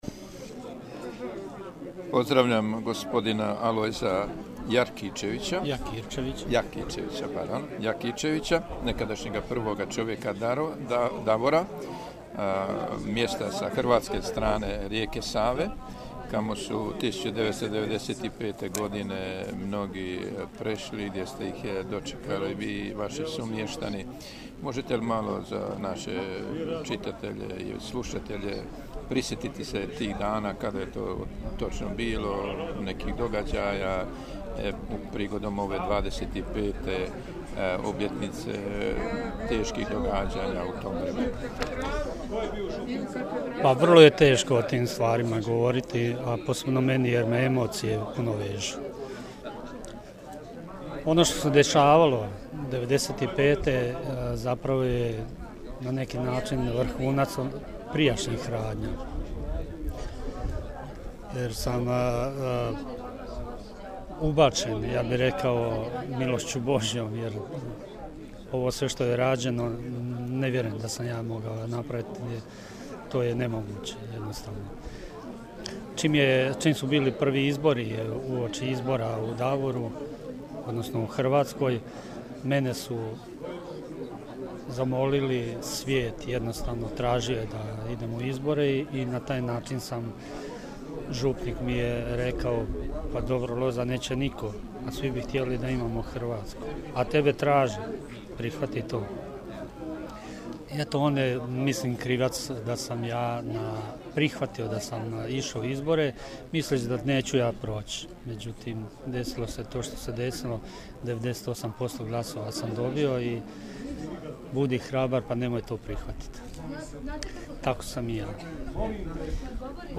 AUDIO: RAZGOVOR S NAČELNIKOM JAKIRČEVIĆEM U POVODU 25 GODINA OD PROGONA BANJOLUČKIH KATOLIKA - BANJOLUČKA BISKUPIJA